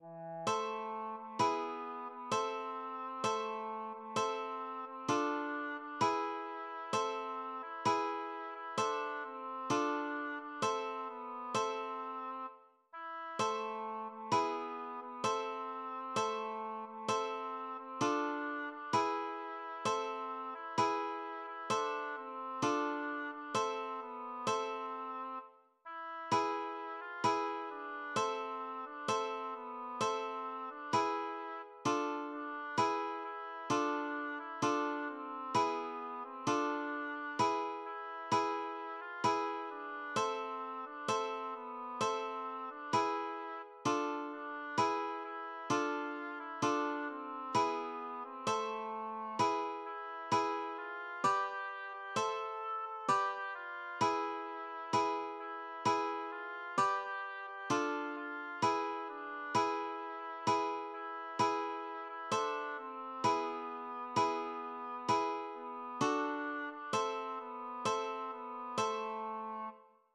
LilyPond 🏰" } myMusic = { << \chords { \germanChords \set chordChanges=##t \set Staff.midiInstrument="acoustic guitar (nylon)" s4
bes2:m s4 } \relative c { \time 4/4 \partial 4 \tempo 4=130 \key bes \minor \set Staff.midiInstrument="oboe" f4